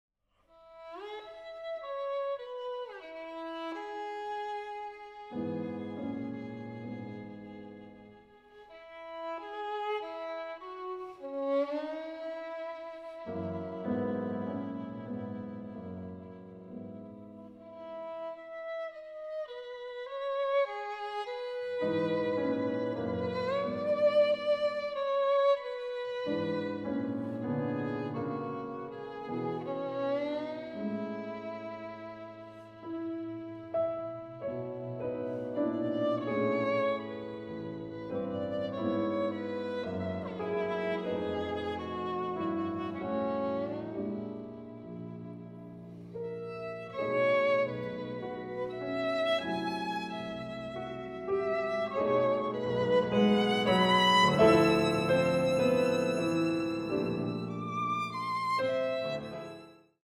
Energico ma espressivo